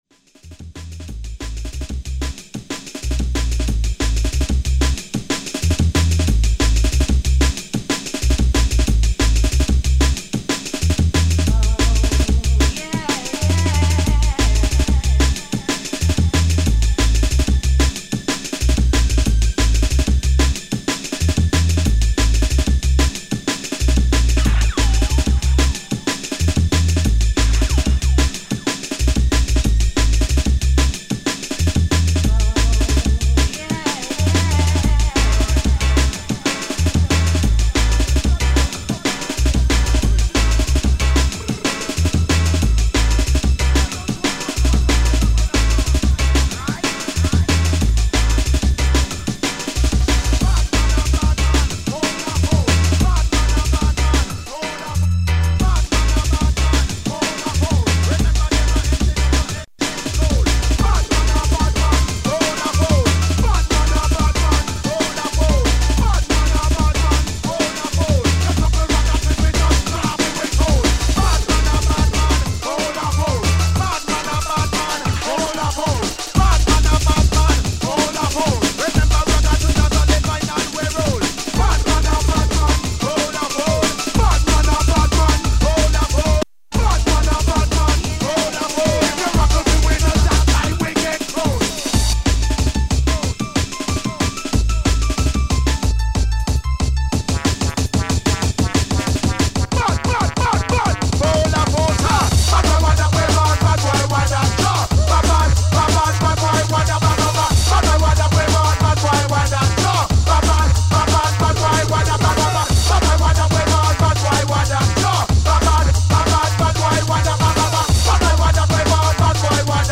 web pages of a czech jungle sound system from Prague